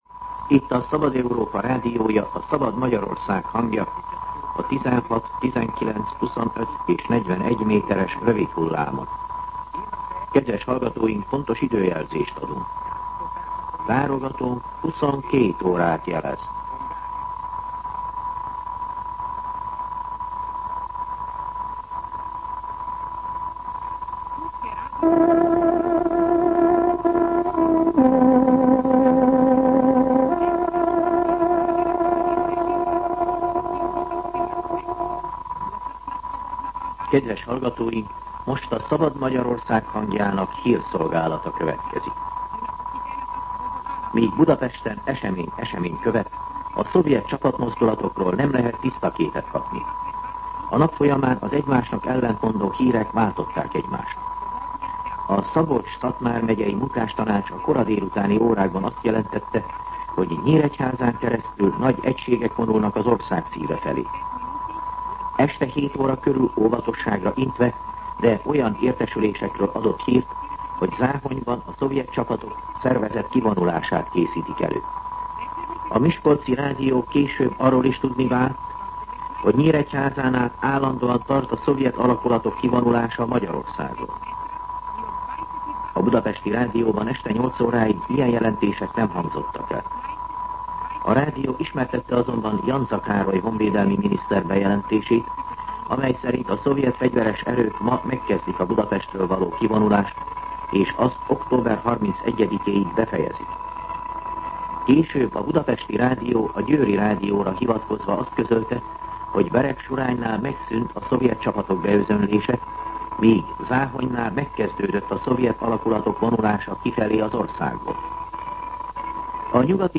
22:00 óra. Hírszolgálat